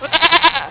Registered Nigerian Dwarf Goats
Click to hear us talk...
goats.wav